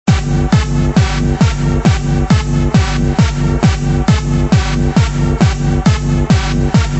it's only an example (made by me with nero wave editor ;-) of the 3 notes of music (tuut, tuut, tuut)...